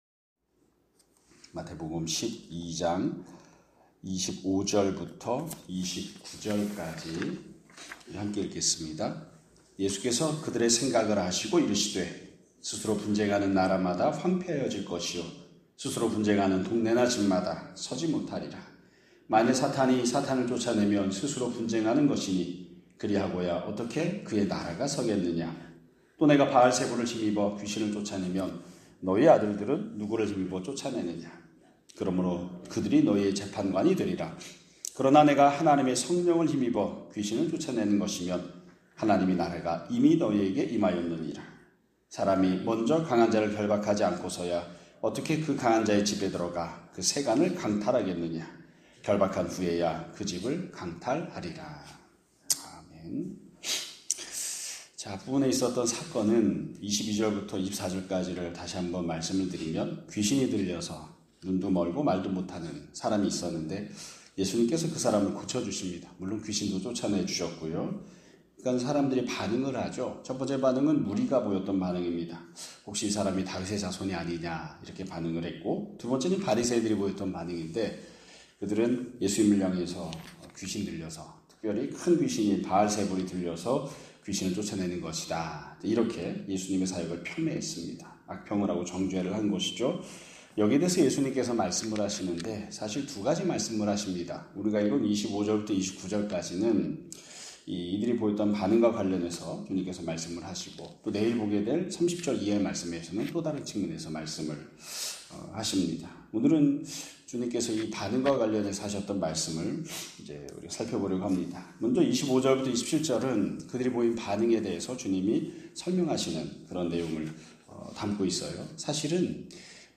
2025년 9월 15일 (월요일) <아침예배> 설교입니다.